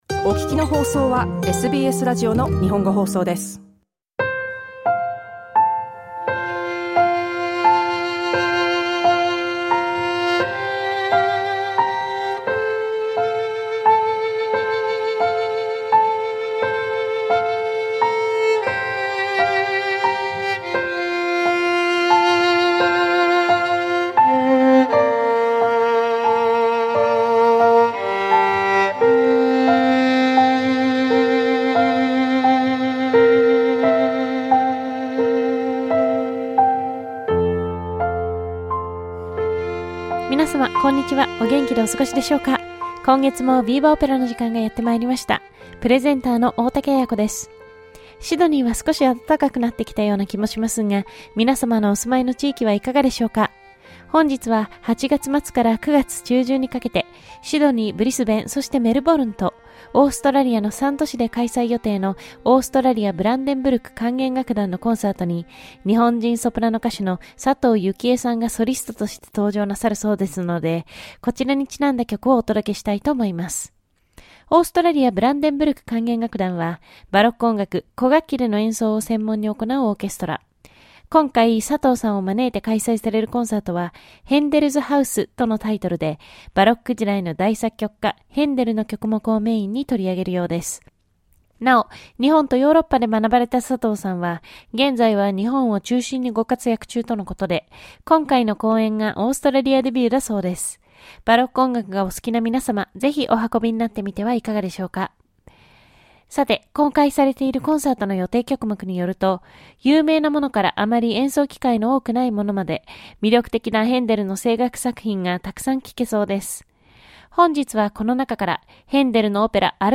Opera 63) VIVA! Opera is a music content broadcast on the 4th and 5th Thursday each month.